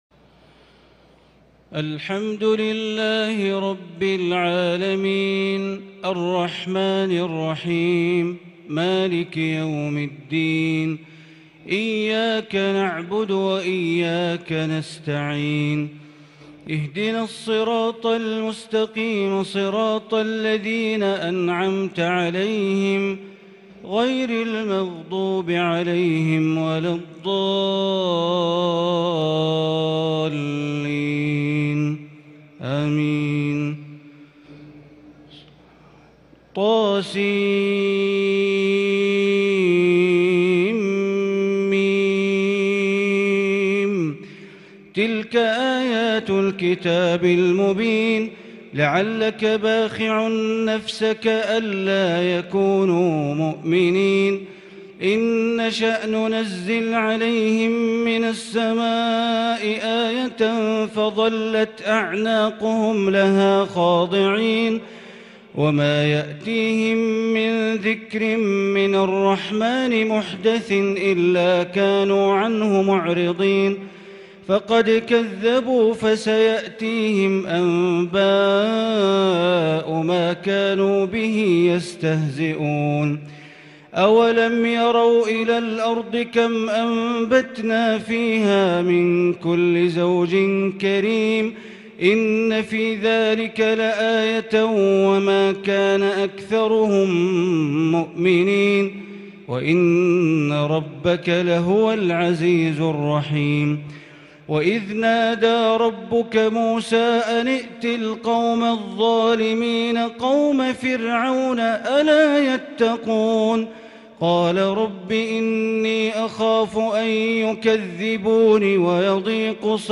تلاوة خاشعة بتنقلات وترنمات بديعة للشيخ بندر بليلة سورة الشعراء كاملة | ليلة 23 رمضان 1442 > تراويح ١٤٤٢ > التراويح - تلاوات بندر بليلة